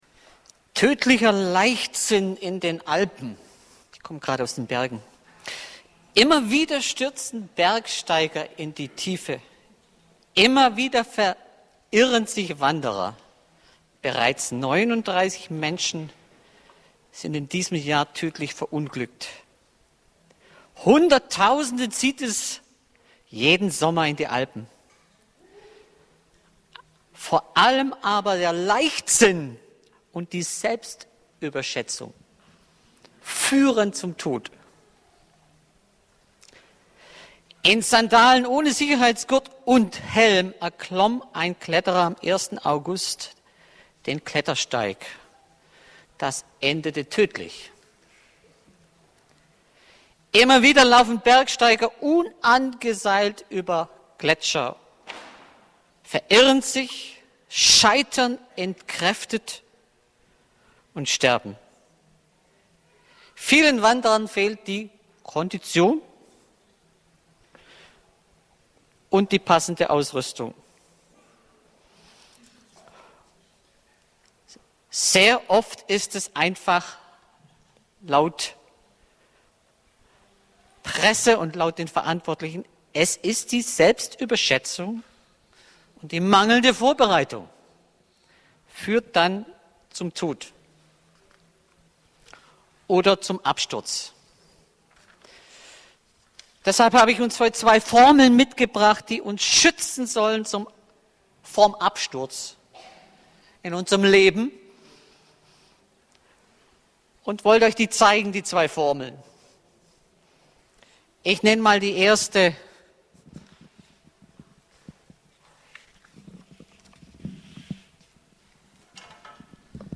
Formeln zum Schutz vorm Absturz Prediger